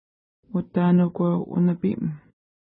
Pronunciation: uta:nukwew unəpi:m
Pronunciation